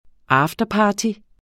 Udtale [ ˈɑːfdʌˌpɑːti ]